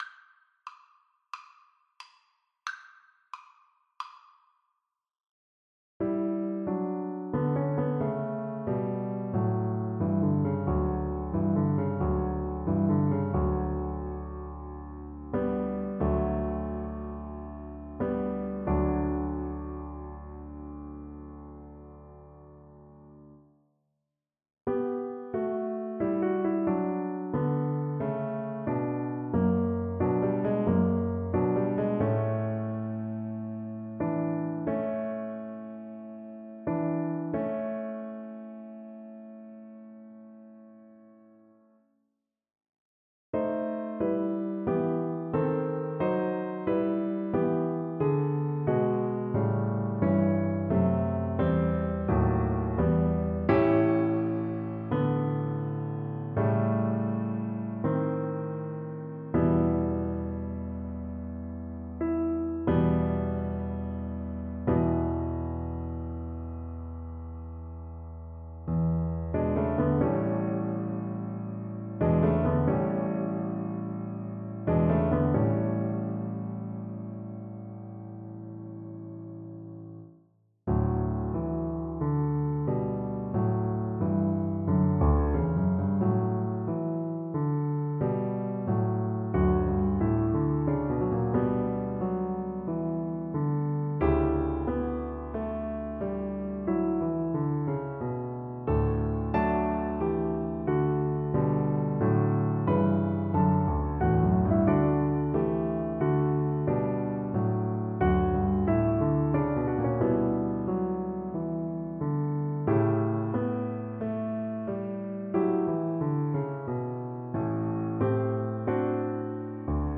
Classical Wagner, Richard Siegfried Idyll Violin version
Violin
Ruhig bewegt = c. 90
4/4 (View more 4/4 Music)
D major (Sounding Pitch) (View more D major Music for Violin )
Classical (View more Classical Violin Music)